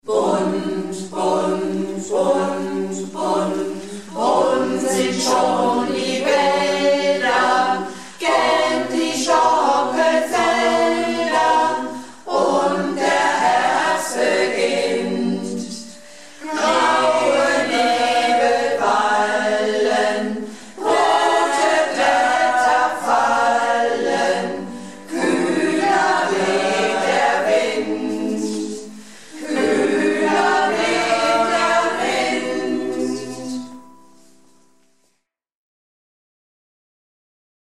Malle Diven - Probe am 27.09.16